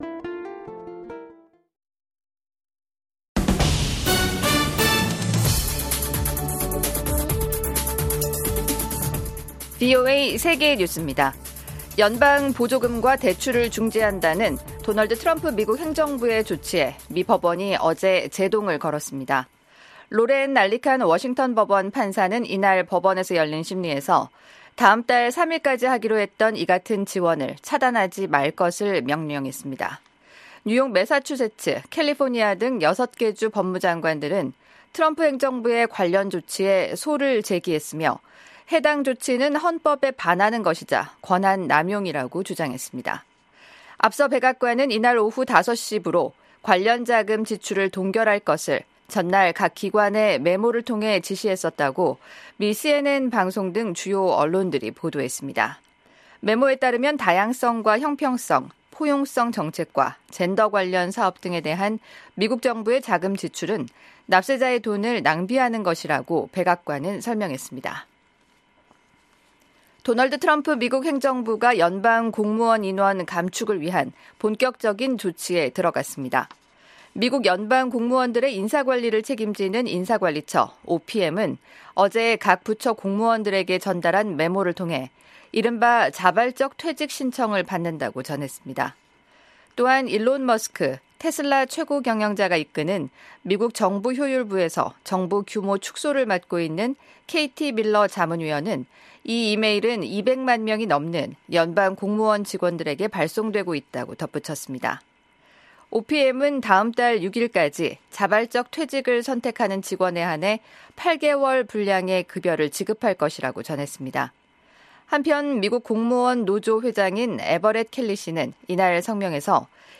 세계 뉴스와 함께 미국의 모든 것을 소개하는 '생방송 여기는 워싱턴입니다', 2025년 1월 29일 저녁 방송입니다. 도널드 트럼프 미국 행정부가 거의 모든 대외 원조를 일시 중단한 가운데 국제 사회에서 우려가 제기되고 있습니다. 미국 연방 판사가 트럼프 정부의 연방 보조금과 대출금 동결 집행을 일시 차단했습니다. 미국 정부는 또 2월 6일까지 퇴직 의사를 밝히는 연방 공무원들에게 최대 8개월 치 급여 보장을 제안했습니다.